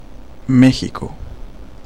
Ääntäminen
Vaihtoehtoiset kirjoitusmuodot Mejico Synonyymit Mexico City Ääntäminen US UK : IPA : /ˈmɛk.sɪ.kəʊ/ Tuntematon aksentti: IPA : /ˈmɛk.sɪ.koʊ/ Lyhenteet ja supistumat (laki) Mex.